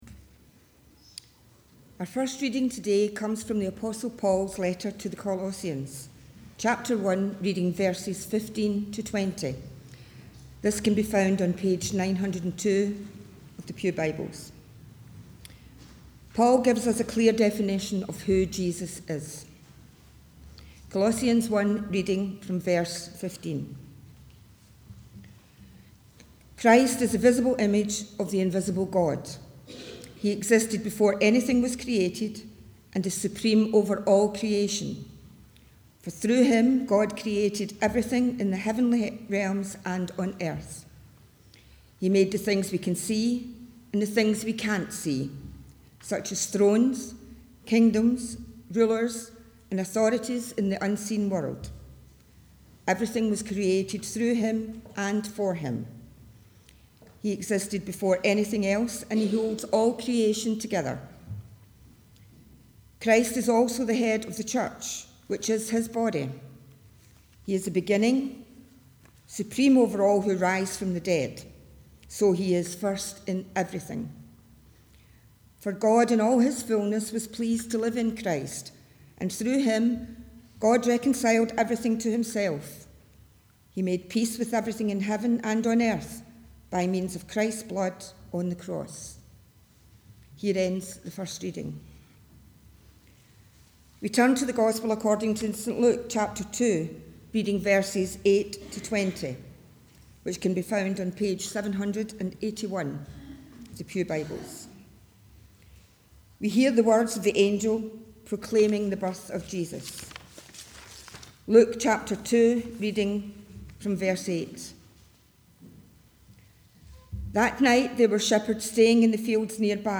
The Scripture Readings prior to the Sermon are Colossians 1: 15-20 & Luke 2: 8-20